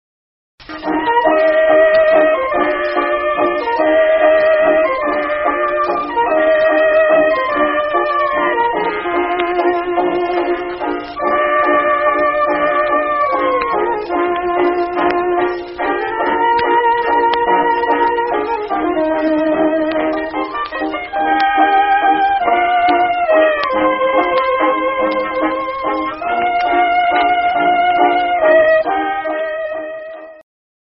Conductor and Violinist